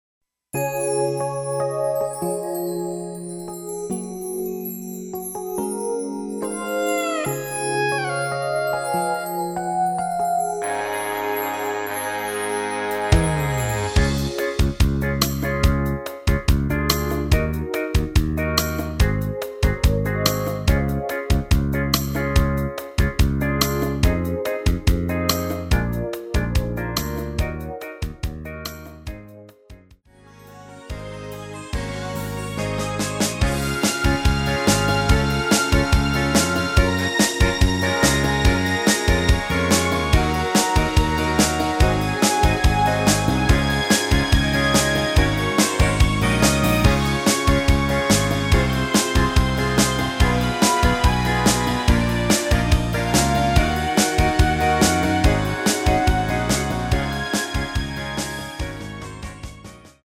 엔딩이 페이드 아웃이라 라이브 하시기 좋게 엔딩을 만들었습니다.
Db
앞부분30초, 뒷부분30초씩 편집해서 올려 드리고 있습니다.
중간에 음이 끈어지고 다시 나오는 이유는